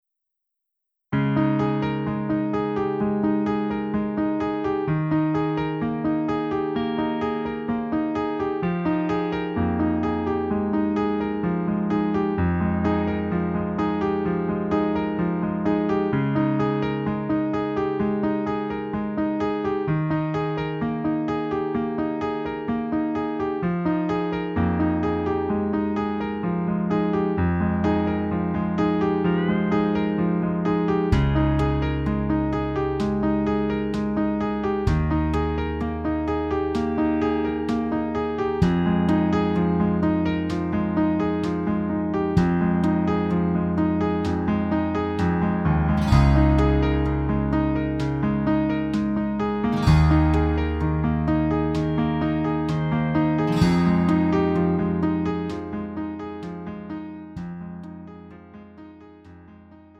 음정 -1키 4:31
장르 가요 구분 Lite MR